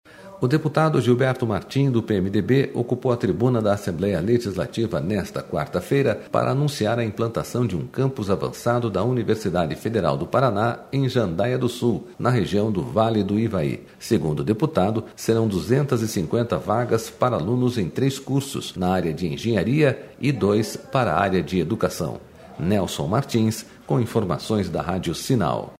O deputado Gilberto Martin, do PMDB, ocupou a tribuna da Assembleia Legislativa nesta quarta-feira para anunciar a implantação de um campus avançado da UFPR em Jandaia do Sul, na região do Vale do Ivaí.// Segundo o deputado, serão 250 vagas para alunos em três cursos da área de engenharia e dois par...